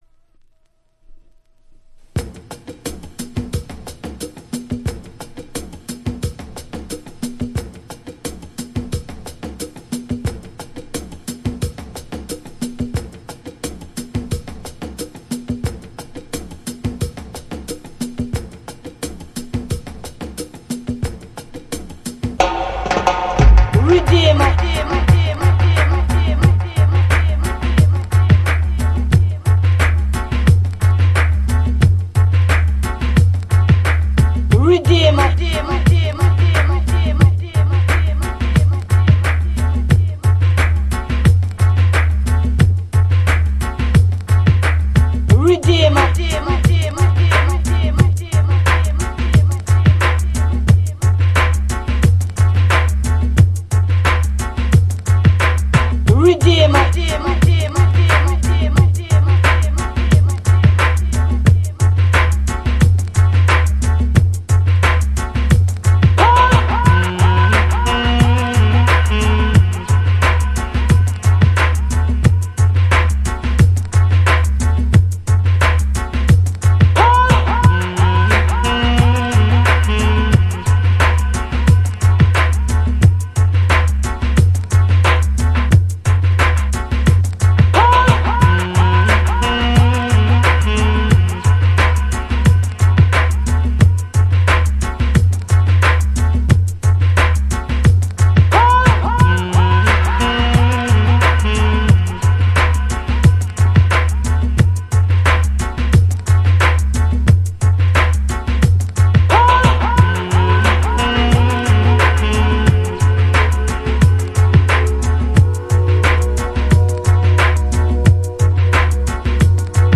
TOP > Early House / 90's Techno > VARIOUS